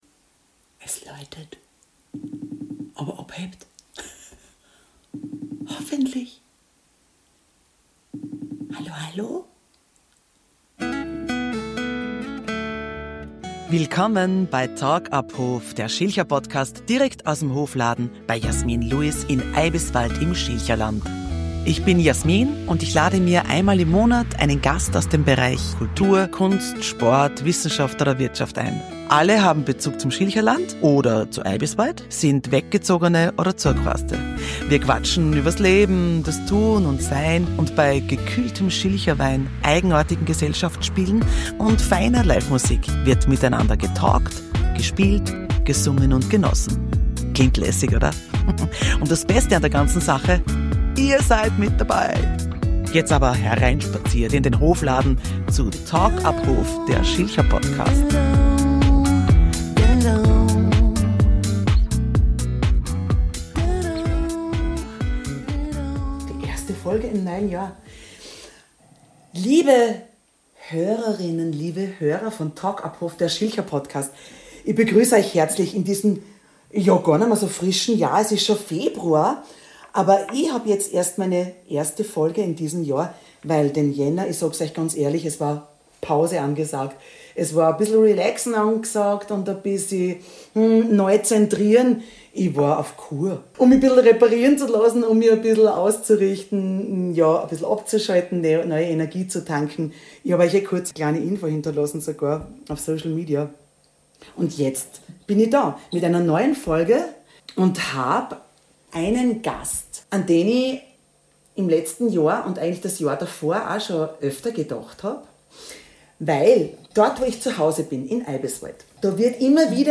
Ein aufregendes Gespräch über Neuanfänge, Heimat und die tiefe Verbundenheit zu alten Freunden.